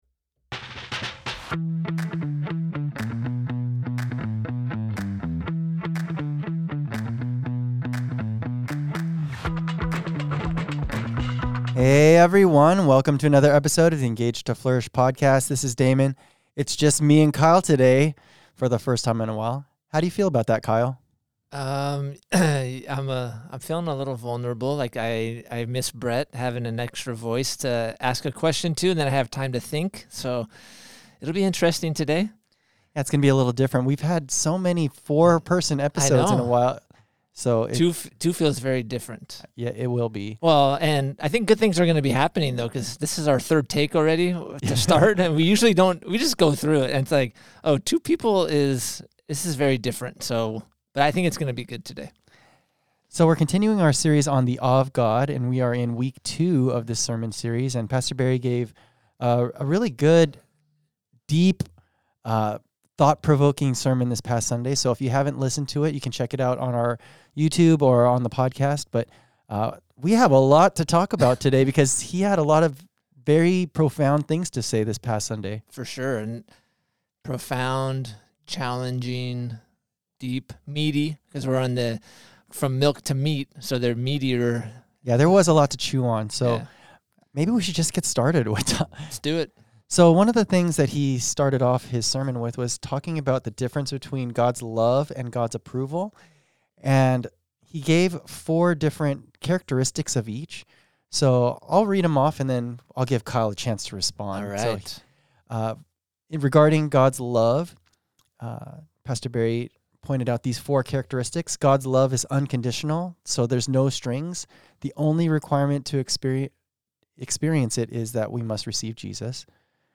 Check out this weeks DEEP discussion.